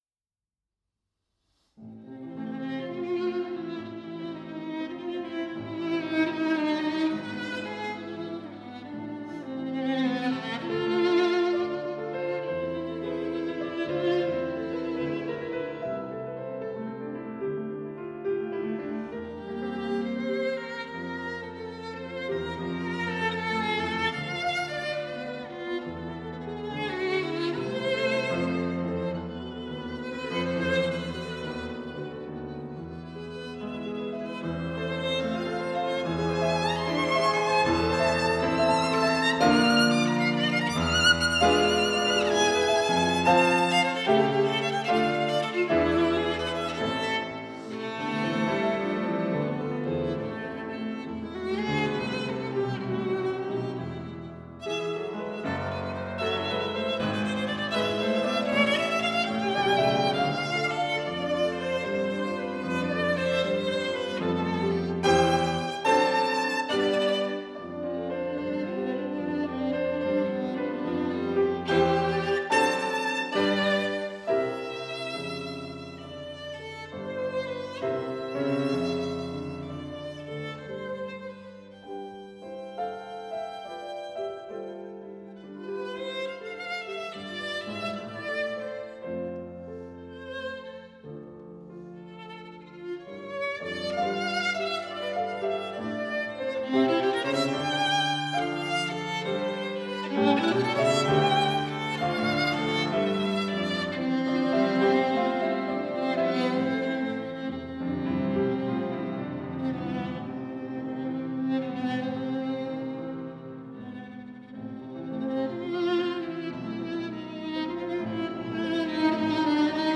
Schumann: Violin Sonata No.1, 1st mov. Piano